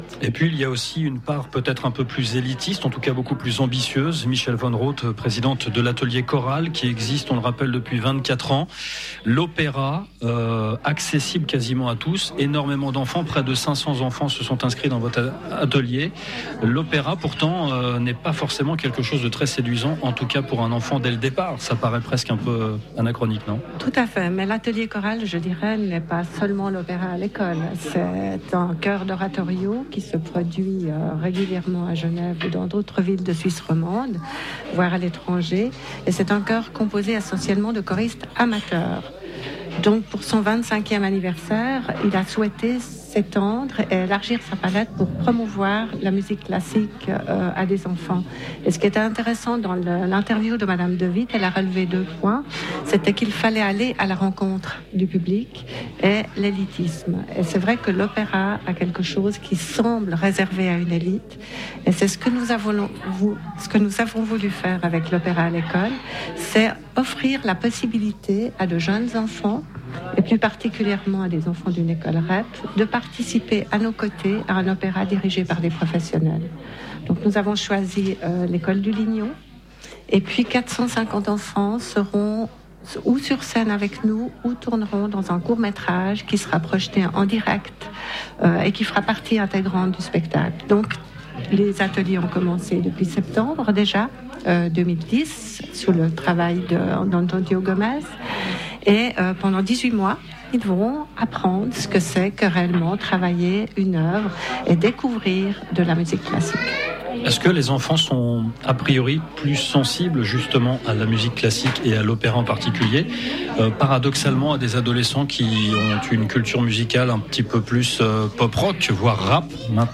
Radio Cité, interview depuis le Café de la Julienne le 14 déc. 2010 (4 ½ min.)